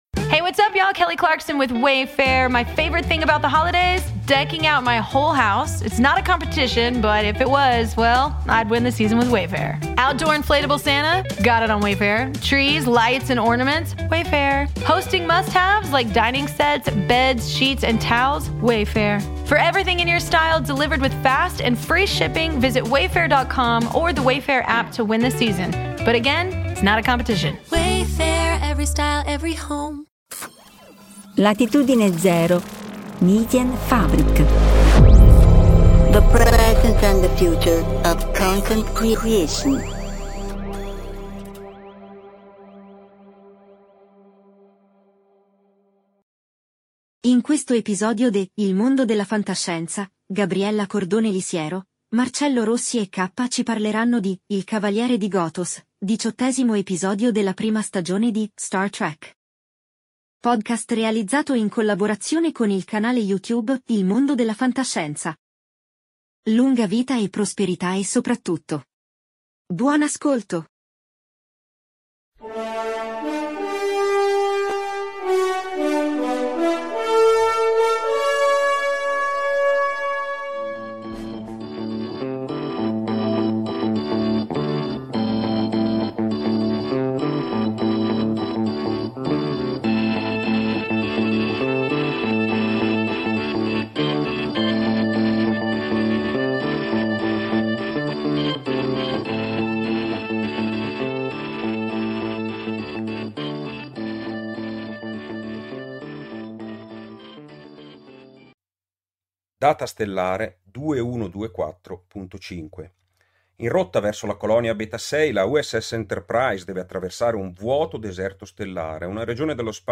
🎙FSC952 - Incontro con l’ospite d’onore Gary Jones (Deepcon 23, 19.3.2023).